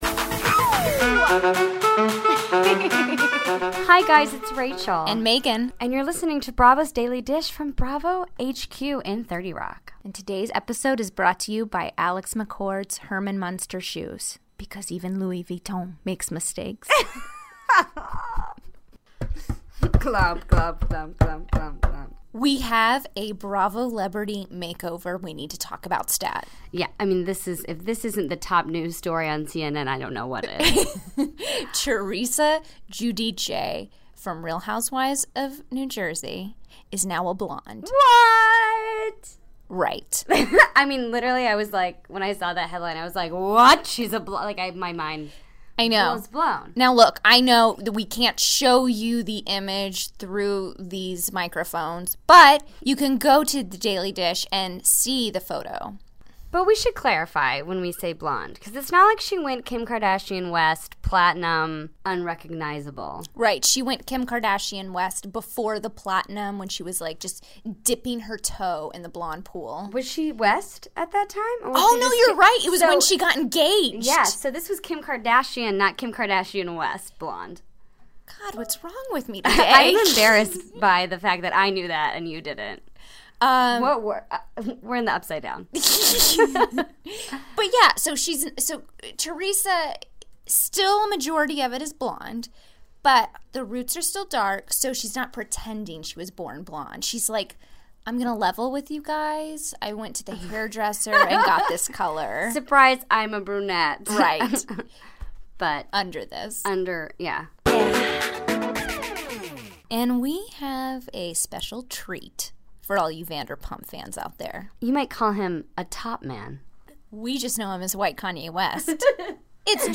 Wednesday, February 1, 2017 - From Bravo HQ in New York City, we're talking all things Vanderpump Rules with the Top Man himself, James Kennedy. He joins us in the studio to weigh in on Jax Taylor, Sheana and Shay's break up, and Lala's mystery man.